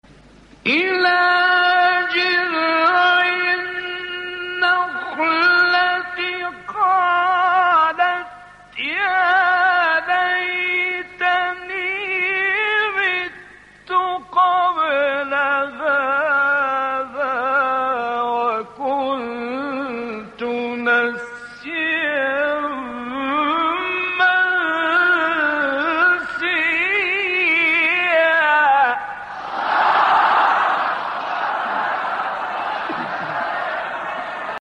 تلاوت در کانال‌های قرآنی/
گروه شبکه اجتماعی: مقاطعی صوتی از راغب مصطفی غلوش که در مقام حسینی اجرا شده است، می‌شنوید.
این مقاطع که در مقام حسینی اجرا شده‌اند، در زیر ارائه می‌شوند.